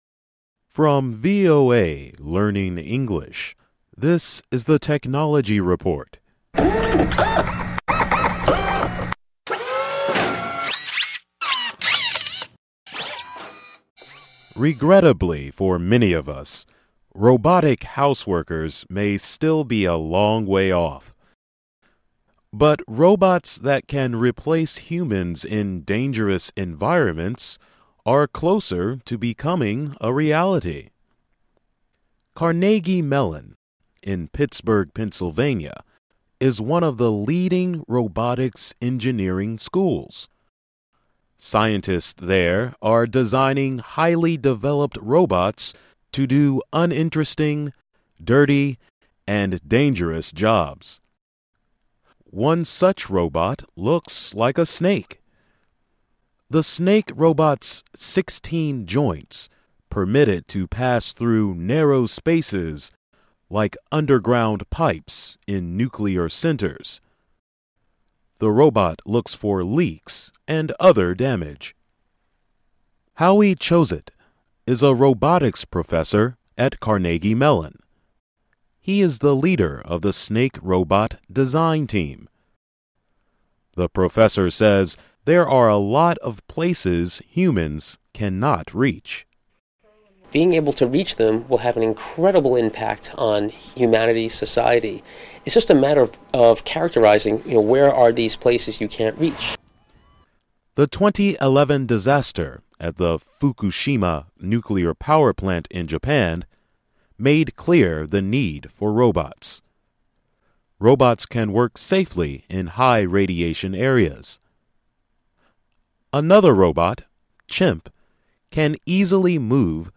VOA Special English, Technology Report, Snake Robots